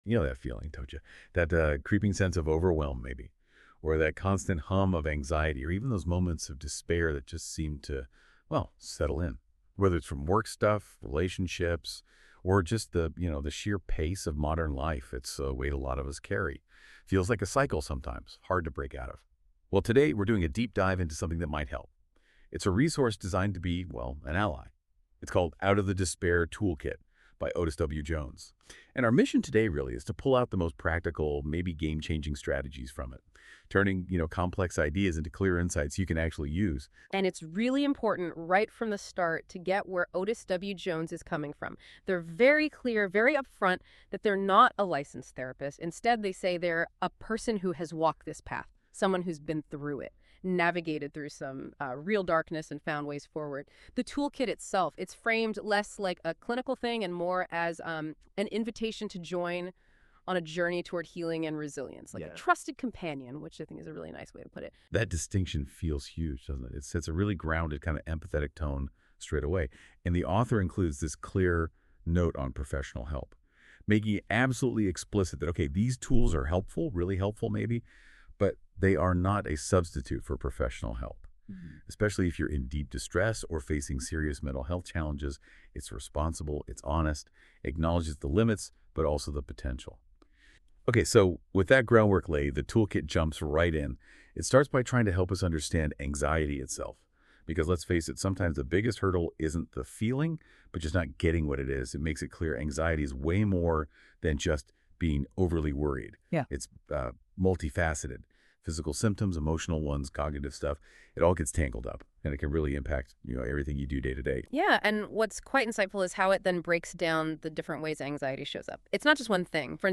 Explore the CSZone Podcast Series, where engaging conversations transform our eBooks into informative audio discussions. Tune in to navigate mental health challenges and empower your journey toward well-being.